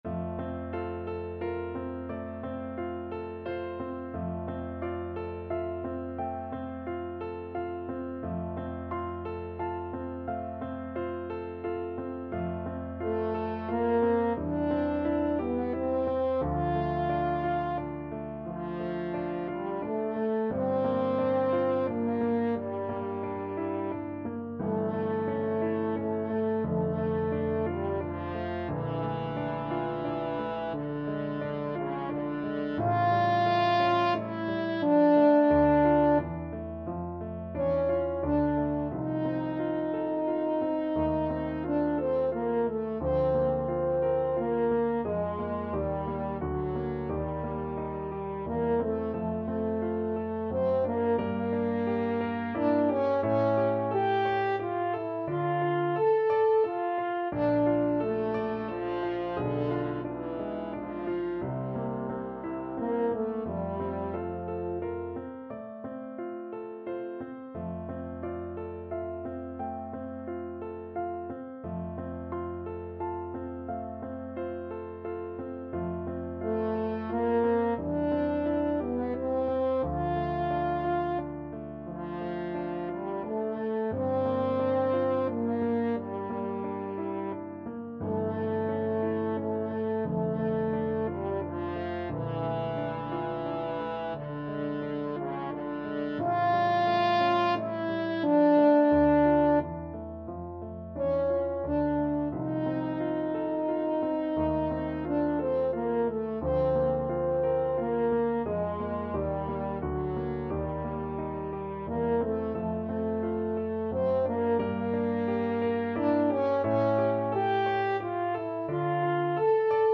~ = 88 Andante
6/4 (View more 6/4 Music)
Classical (View more Classical French Horn Music)